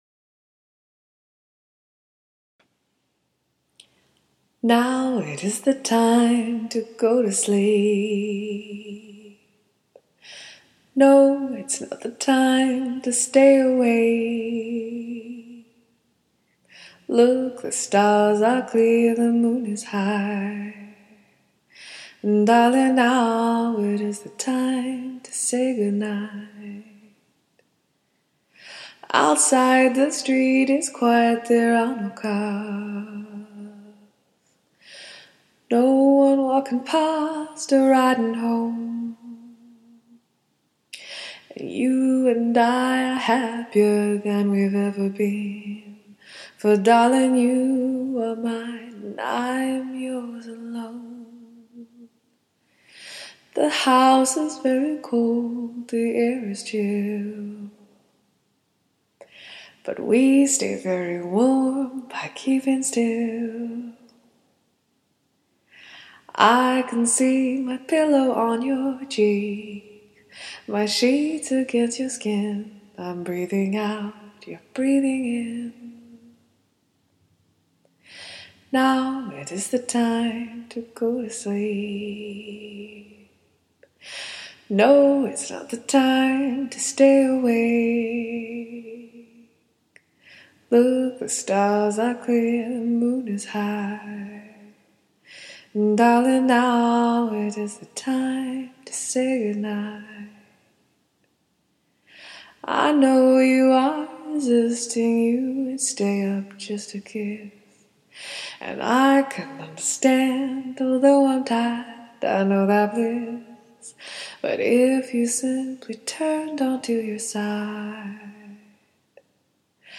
Jesus saves, but reverb hides a multitude of sins.